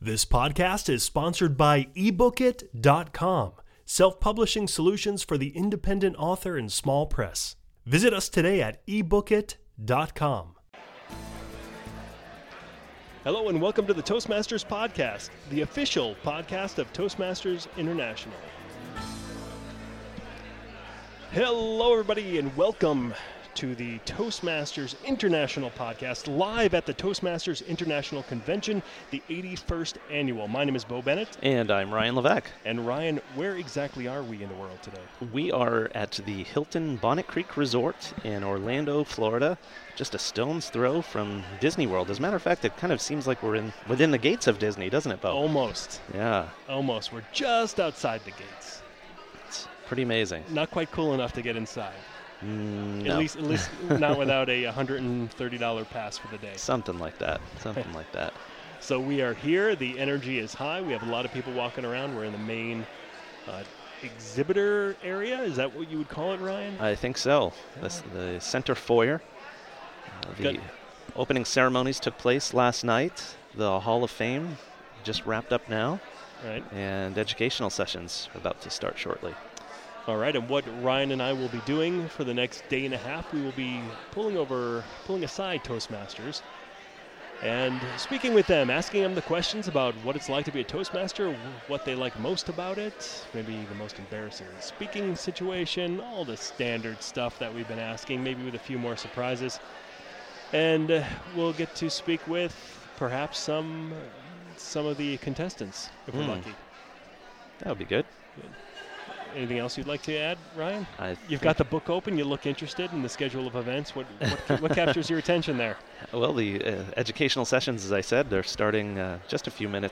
Toastmasters Podcast #060: 2012 International Convention Live Interviews | Toastmasters Podcast